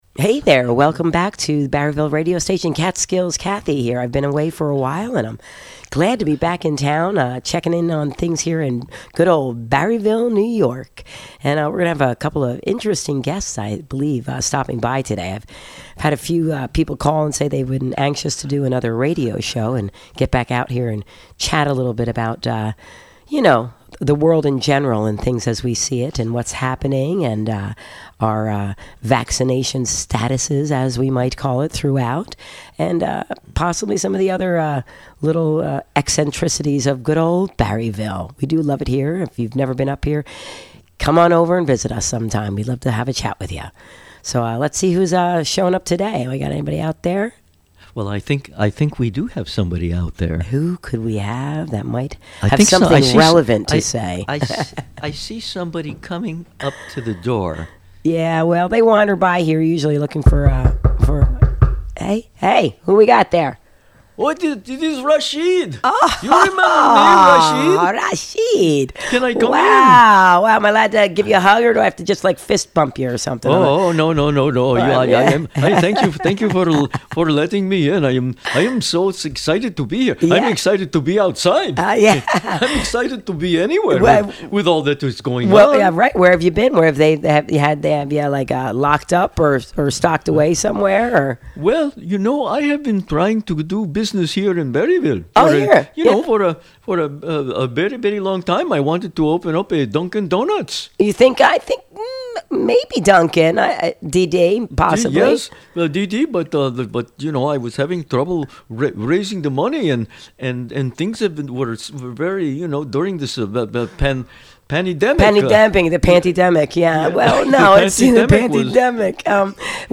Outrageous Talk & Comedy
Interview